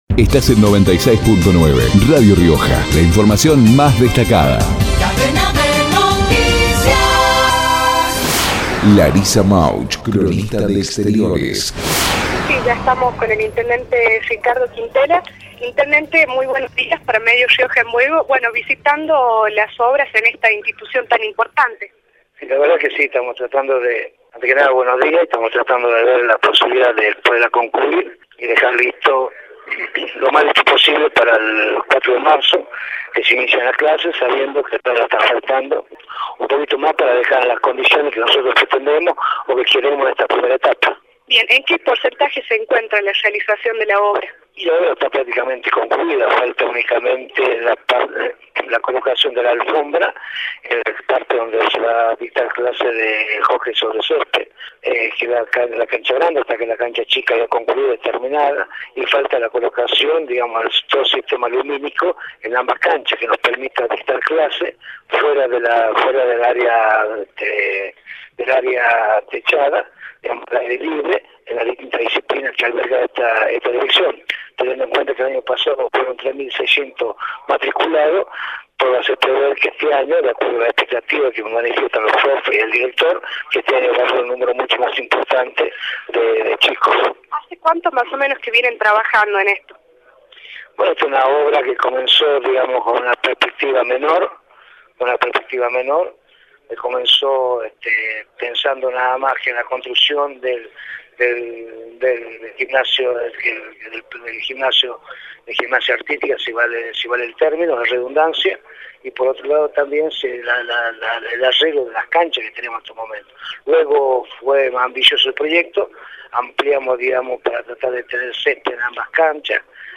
Ricardo Quintela, intendente, por Radio Rioja